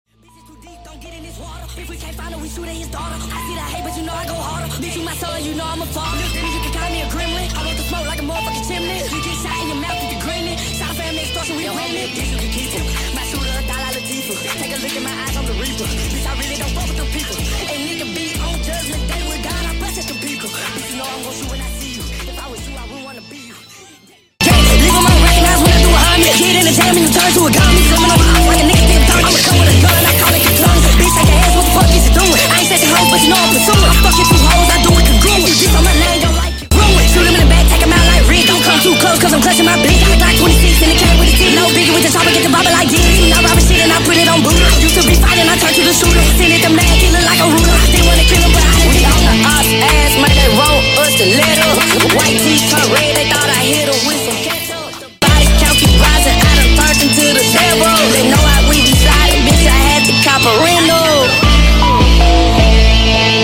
guitar remix sped up